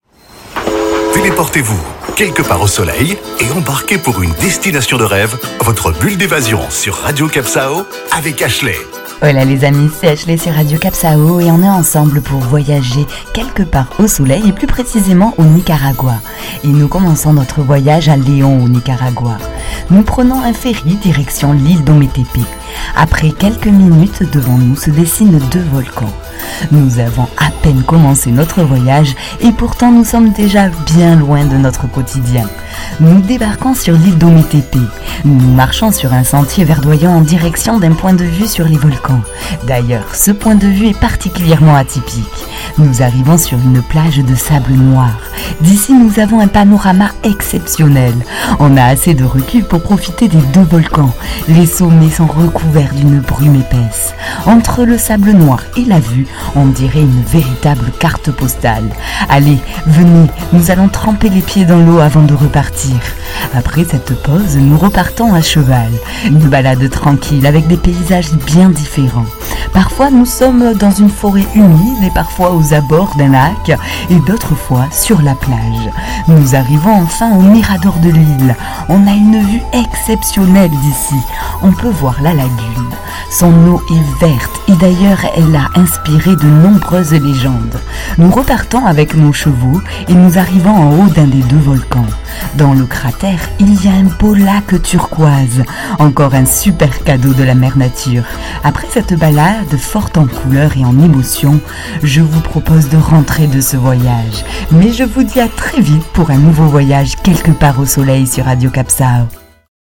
Carte postale sonore : prêts à avoir le souffle coupé ? Direction l'île d'Ometepe où les différents points de vue et miradores vont vous subjuguer.